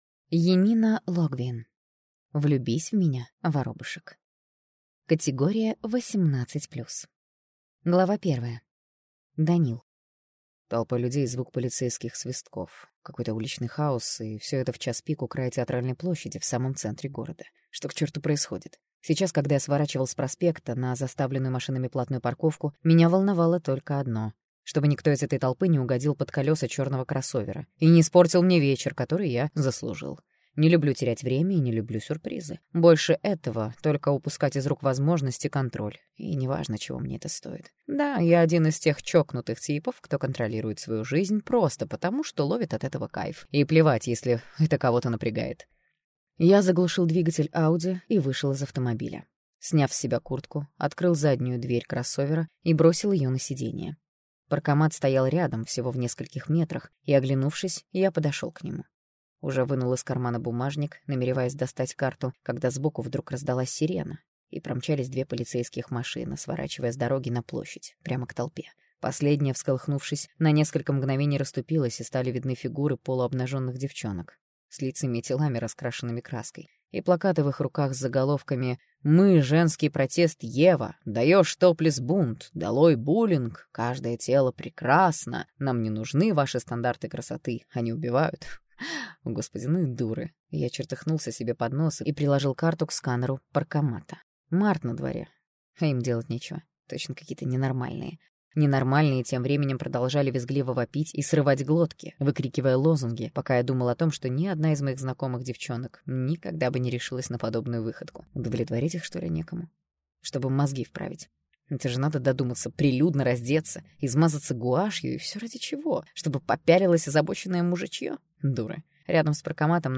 Аудиокнига Влюбись в меня, Воробышек!
Прослушать и бесплатно скачать фрагмент аудиокниги